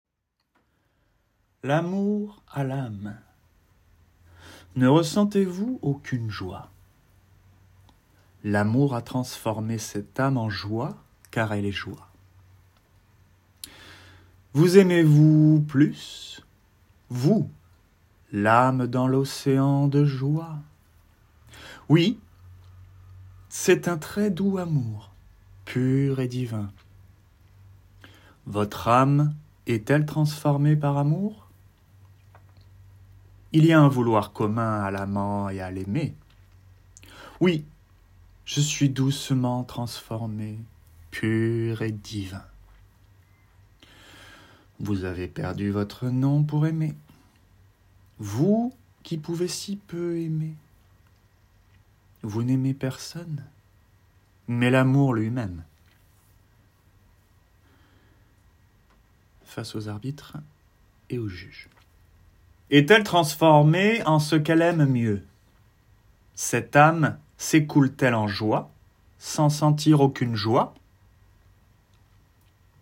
Example: For the first track, we are at 750 hertz , and so we obtain a Bpm of 45, and it's the note of F#.
The goal is not to get over 2000 hertz, and the necessity to play at low level with the instruments.
*I will only use an harmonic tremolo pedal for this record, combined with the guitar and the Minilogue.